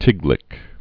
(tĭglĭk)